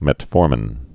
(mĕt-fôrmĭn)